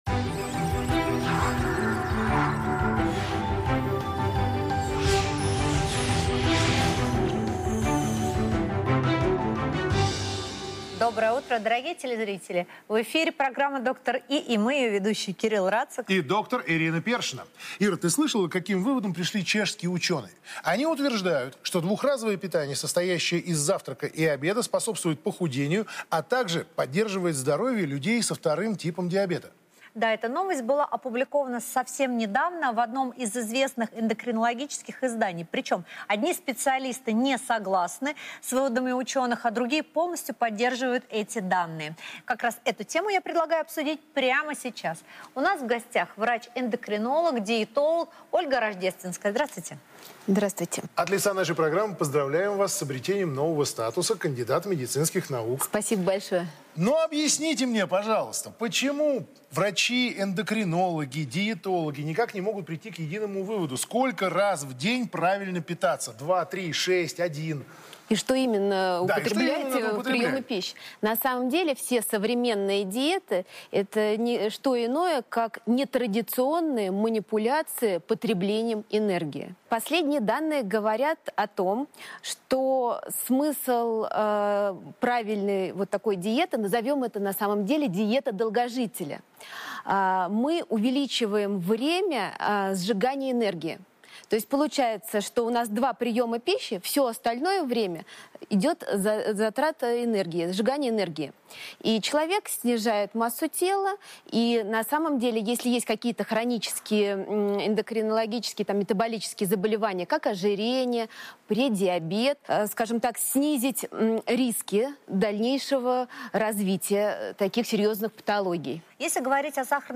Храп как симптом сниженного тестостерона и как следствие импотенция Дата: 24.01.2019 Источник: телеканал ТВЦ.
25:43 вернуться к медиатеке скачать интервью в txt формате скачать интервью в mp3 формате Храп может быть симптомом синдрома обструктивного апноэ сна (СОАС), при котором происходят кратковременные остановки дыхания во сне.